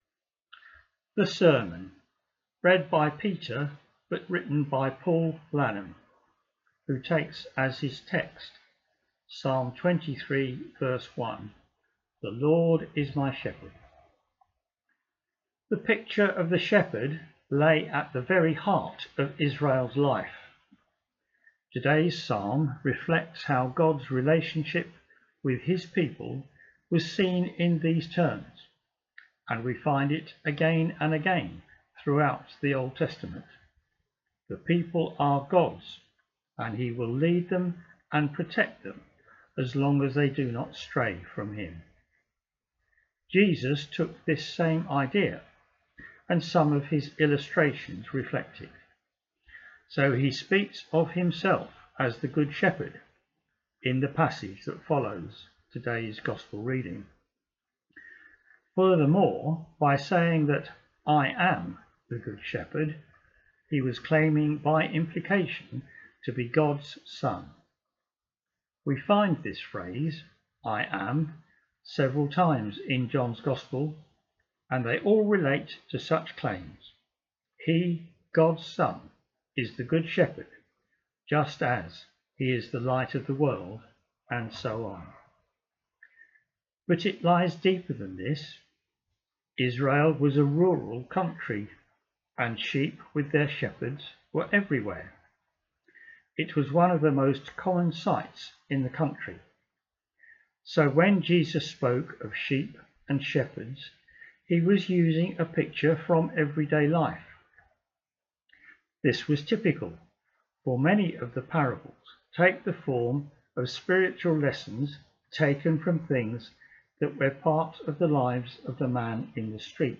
easter-4-sermon.m4a